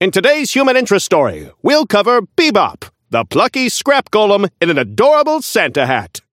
Newscaster voice line - In today's human interest story: We'll cover Bebop, the plucky scrap golem in an adorable Santa hat!
Newscaster_seasonal_bebop_unlock_01.mp3